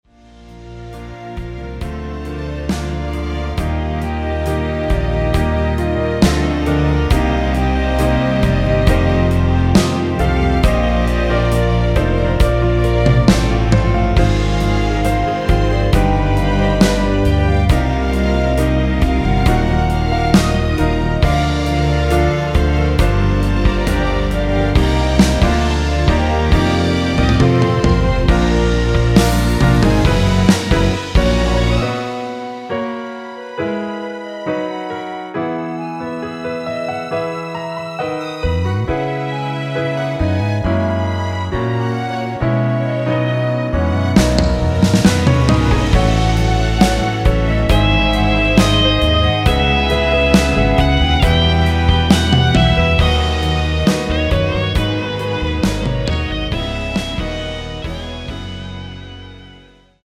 원키에서(-1)내린 (1절+후렴)으로 편곡된 멜로디 포함된 MR입니다.(미리듣기및 가사 참조)
Bb
앞부분30초, 뒷부분30초씩 편집해서 올려 드리고 있습니다.
중간에 음이 끈어지고 다시 나오는 이유는